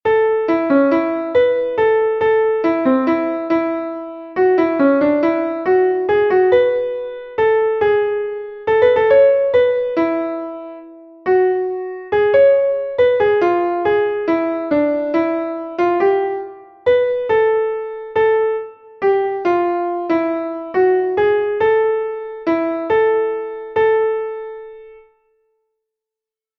Entoación a capella
Imos practicar a entoación a capella coas seguintes melodías.
entonacioncapelaud7._1.mp3